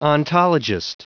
Prononciation du mot ontologist en anglais (fichier audio)
Prononciation du mot : ontologist